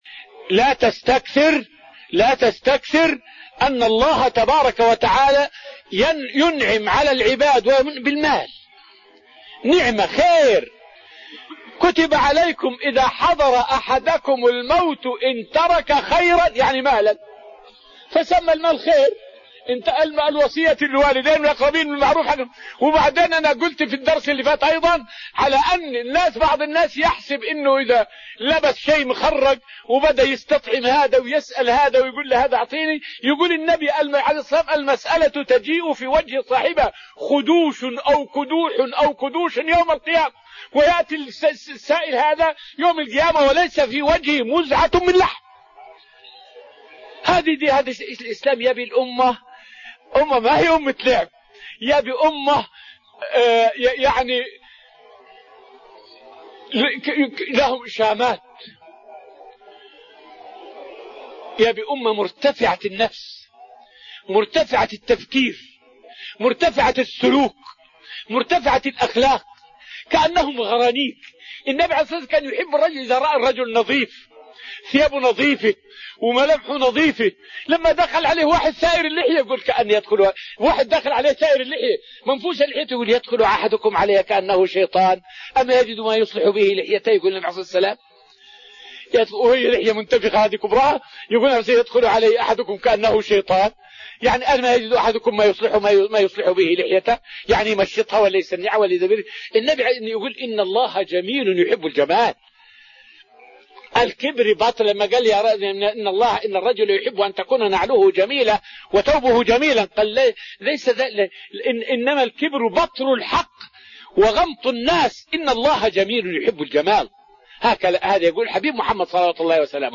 فائدة من الدرس الخامس من دروس تفسير سورة الأنفال والتي ألقيت في رحاب المسجد النبوي حول كيف رأى المشركون المسلمين في معركة بدر.